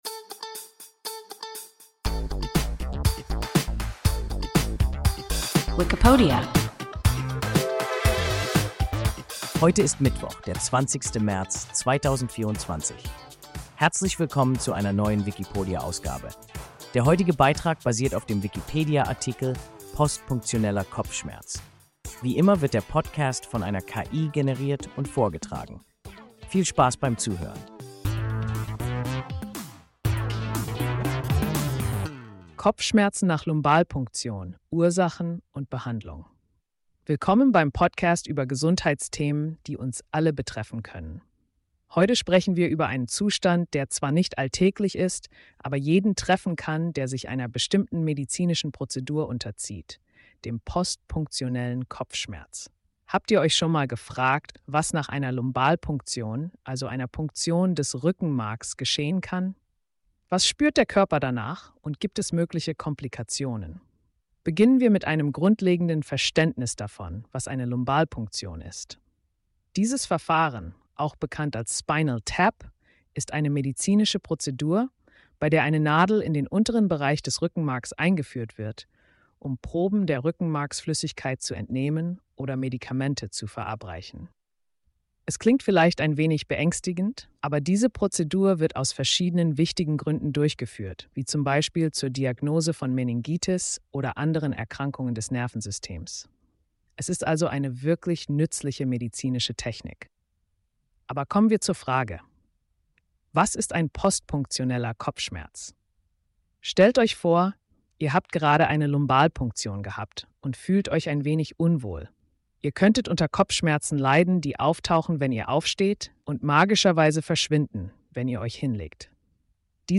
Postpunktioneller Kopfschmerz – WIKIPODIA – ein KI Podcast